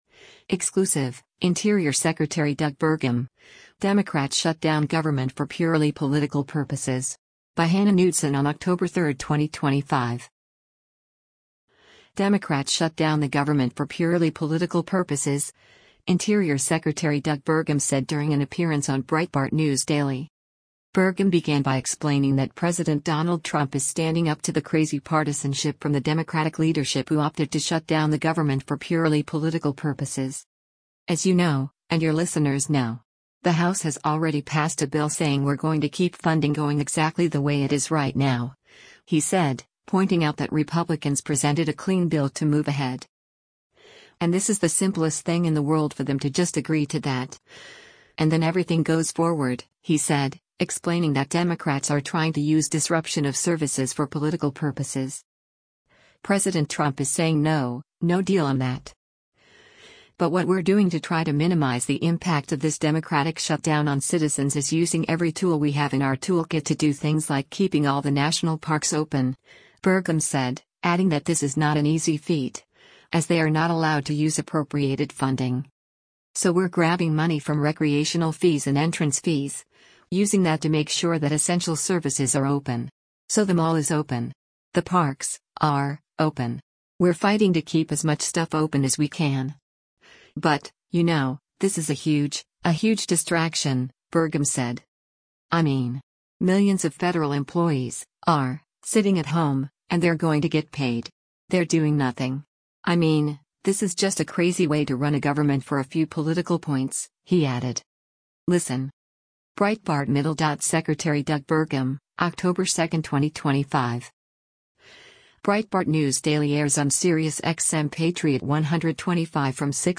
Democrats shut down the government for “purely political purposes,” Interior Secretary Doug Burgum said during an appearance on Breitbart News Daily.